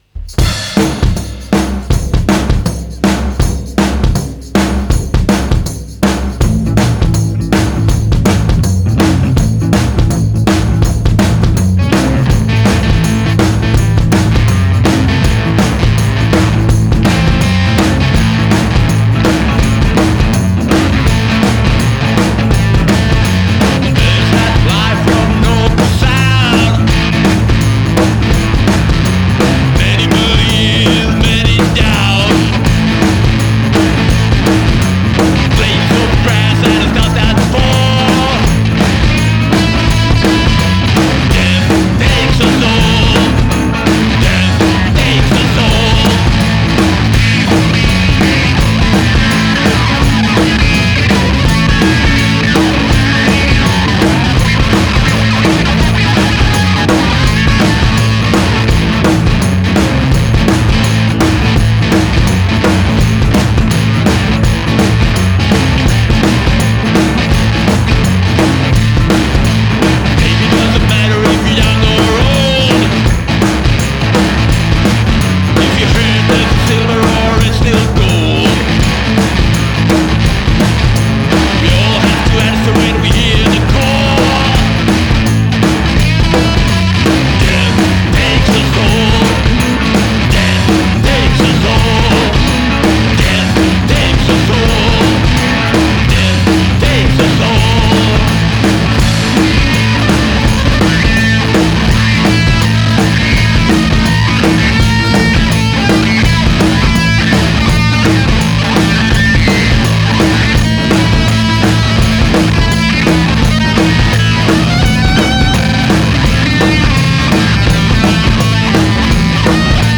Un peu de blues rock pour commencer la semaine ?